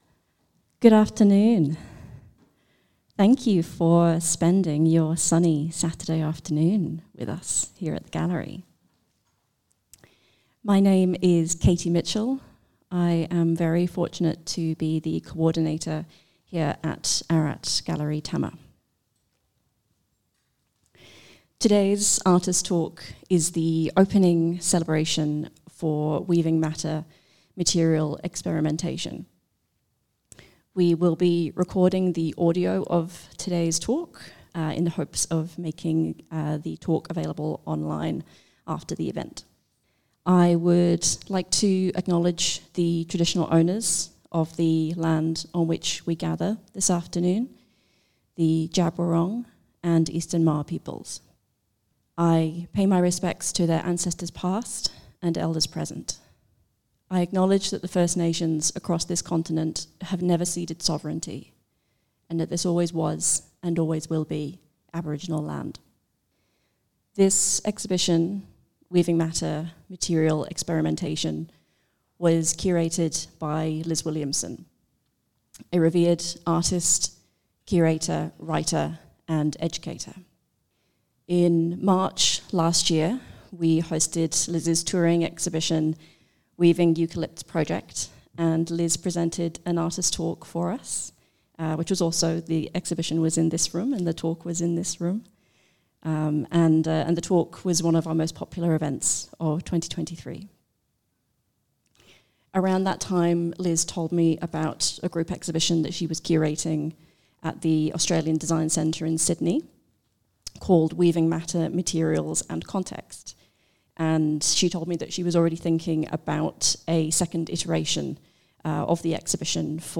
Weaving Matter Opening Artist Talk